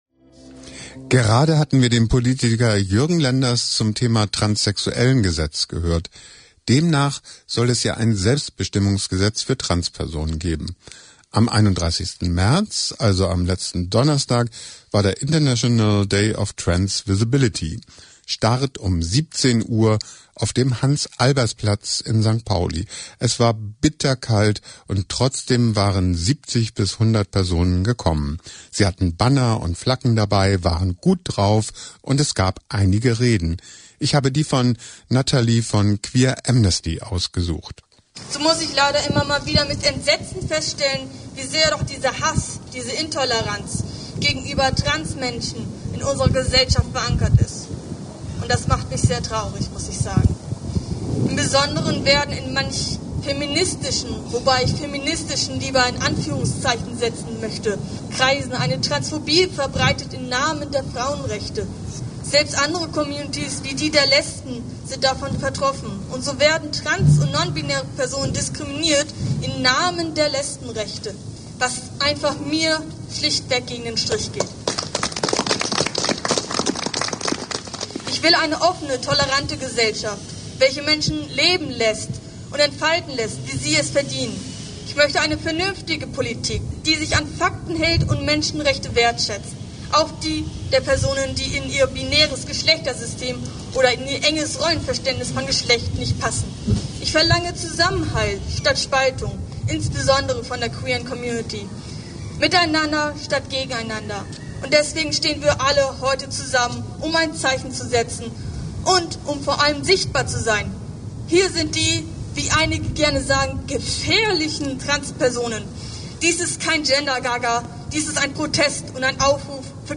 Interview mit Jürgen Lenders (FDP) zum geplanten trans Selbstbestimmungsgesetz